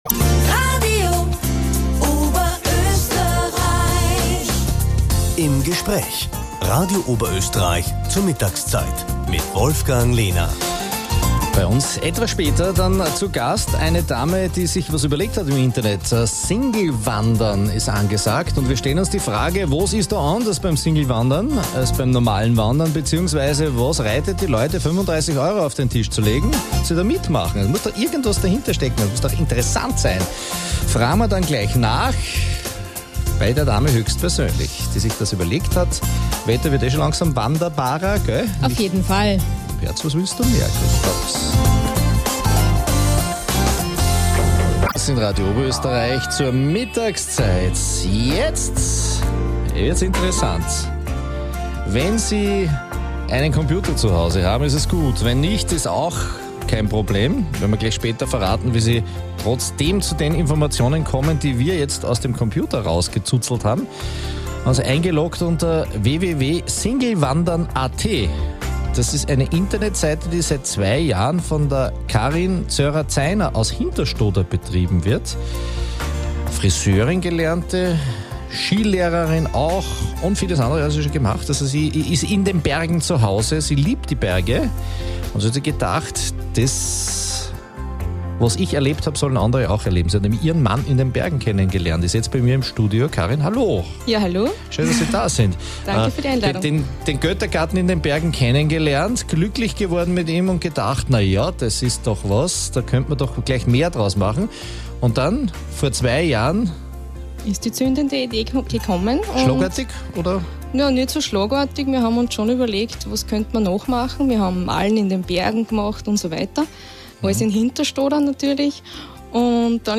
Es war ein interessantes Interview und wir wollen nochmal an alle ein großes Dankeschön ausrichten :-) Danke auch für das MP3 für die Leute die nicht die Gelegenheit hatten das Interview live mitzuhören.